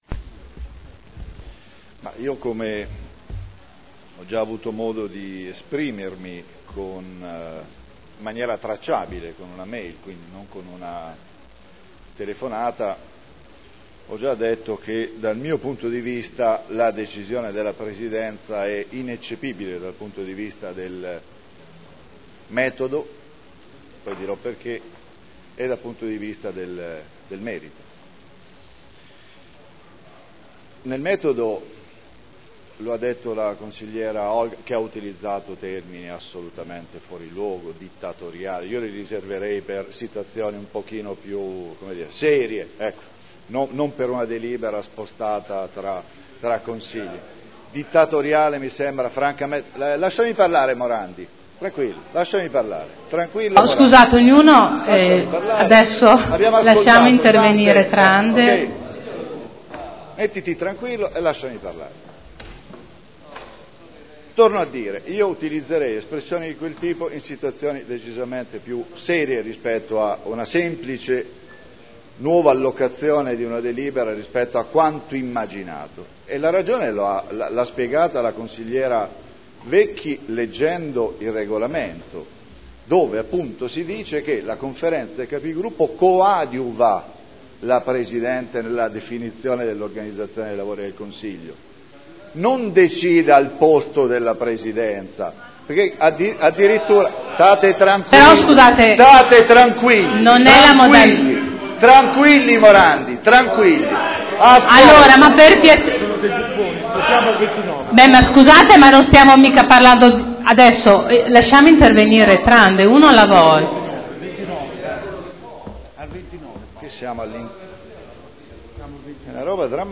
Seduta del 26/11/2012 interviene contro a pregiudiziale Consigliera Vecchi.